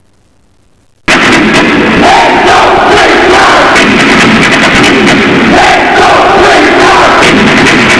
LOTD in Brünn